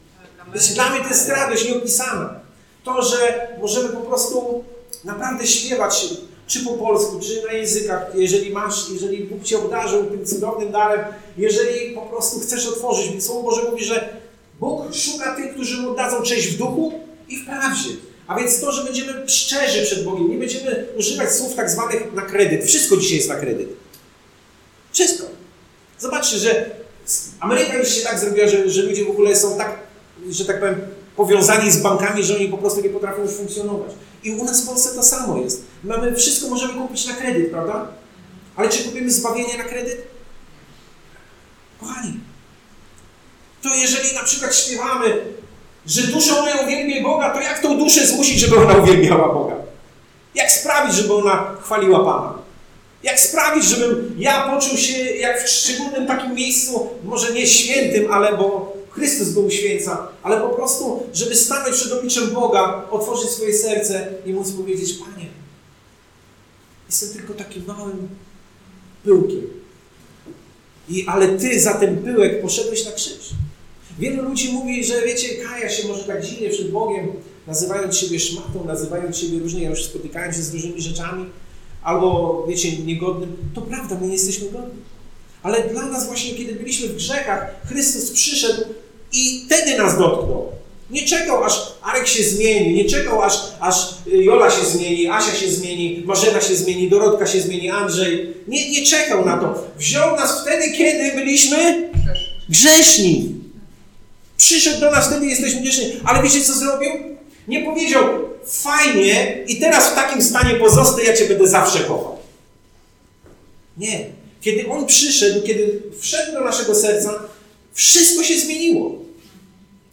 Posłuchaj kazań wygłoszonych w Zborze Słowo Życia w Olsztynie